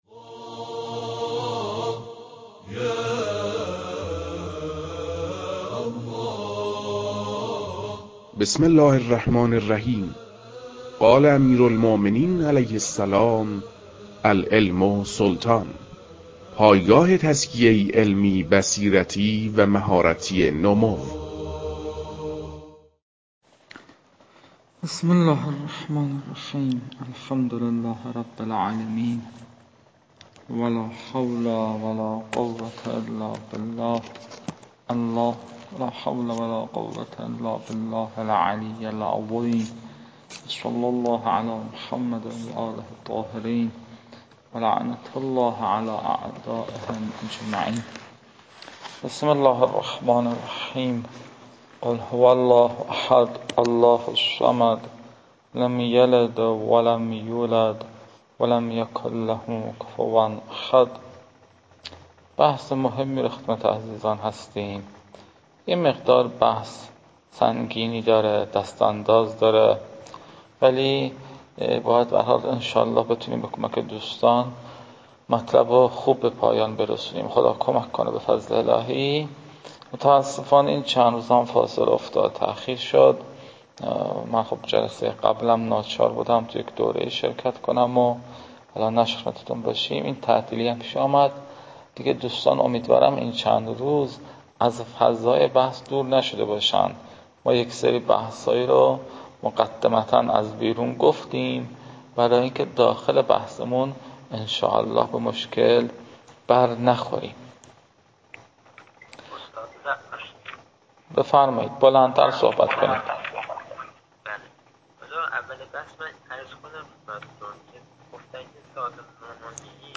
در این بخش، فایل های مربوط به تدریس مبحث رسالة في القطع از كتاب فرائد الاصول متعلق به شیخ اعظم انصاری رحمه الله